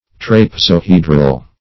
Search Result for " trapezohedral" : The Collaborative International Dictionary of English v.0.48: Trapezohedral \Trap`e*zo*he"dral\, a. Of, pertaining to, or resembling, a trapezohedron.
trapezohedral.mp3